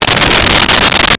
Sfx Crash Firey C
sfx_crash_firey_c.wav